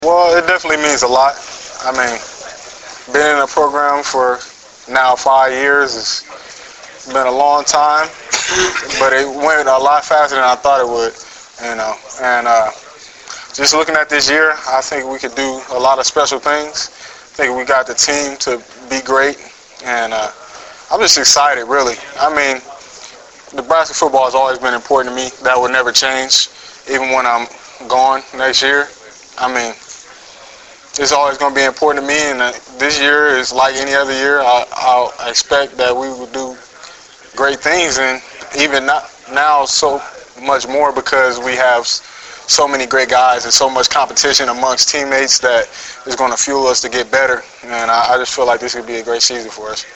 Preseason Press Conference
Memorial Stadium - Lincoln, Neb.